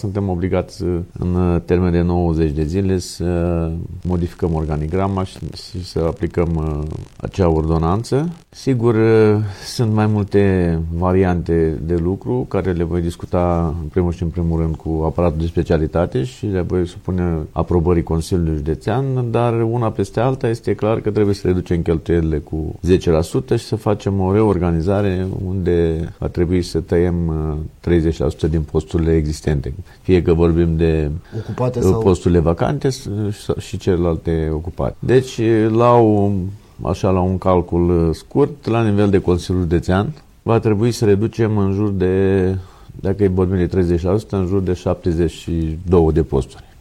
Președintele Consiliului Județean Neamț, Daniel Vasilică Harpa
INSERT-VOCE-HARPA.mp3.mp3